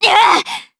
DarkFrey-Vox_Attack3_jp.wav